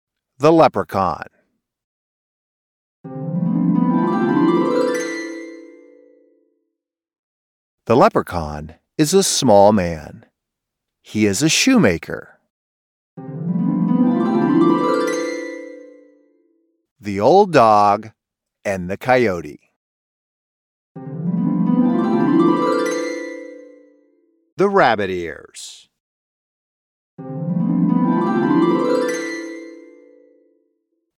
• For voiceovers – a home studio with acoustic treatment, CM25 MkIII condenser microphone, Focusrite Scarlett Si2 interface, Adobe Audition Software.
Narration for Children Stories
Narration-for-childrens-stories.mp3